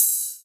ride.ogg